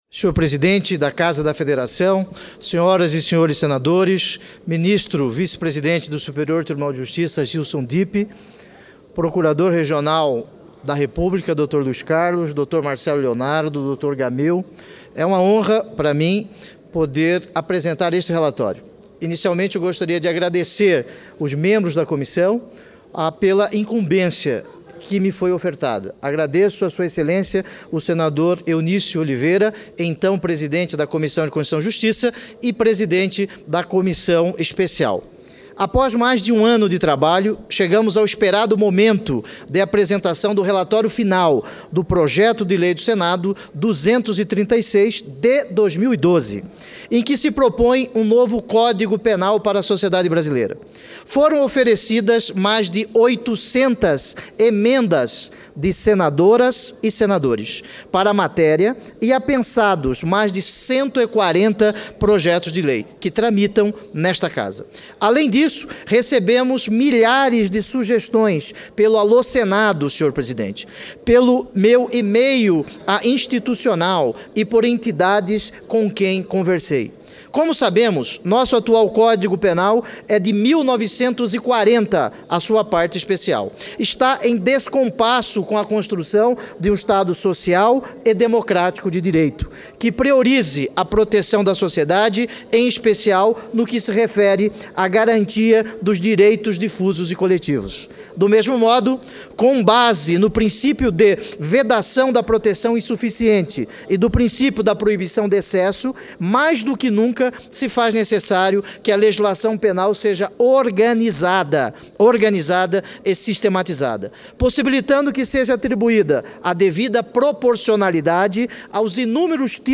Pronunciamento do senador Pedro Taques